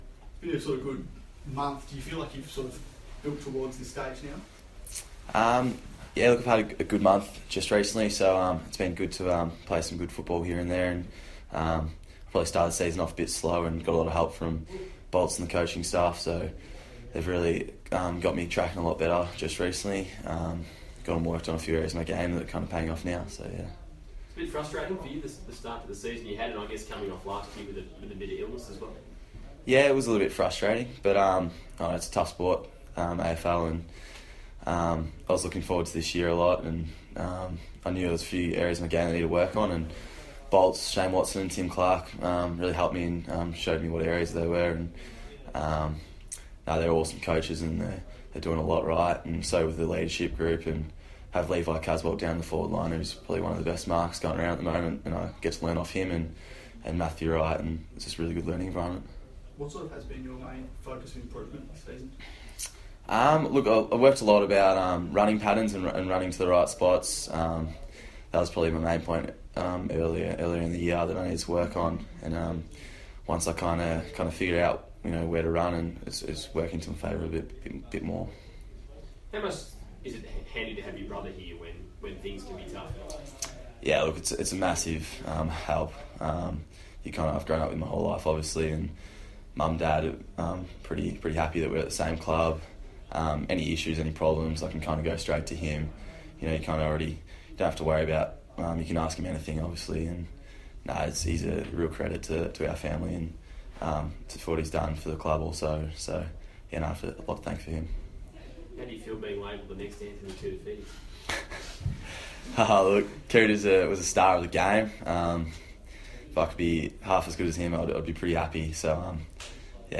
Charlie Curnow press conference - July 11
Carlton youngster Charlie Curnow faces the media after receiving the Round 16 Rising Star nomination.